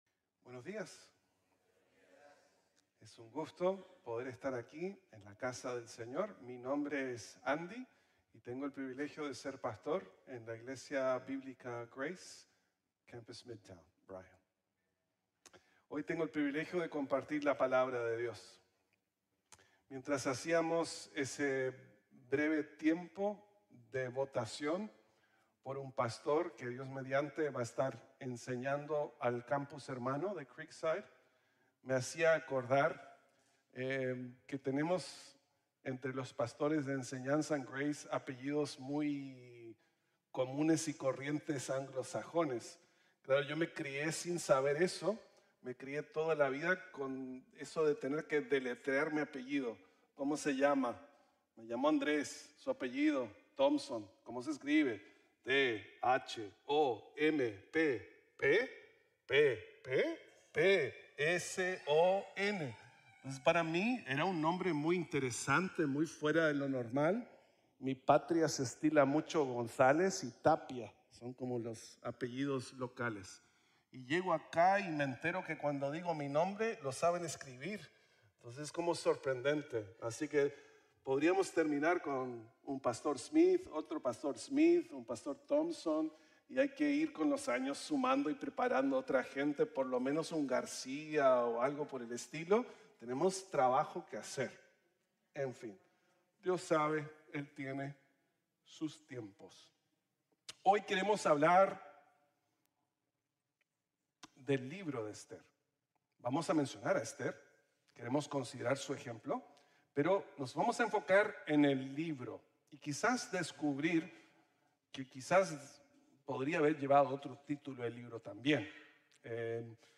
Ester: Una Ocasión Como Esta | Sermon | Grace Bible Church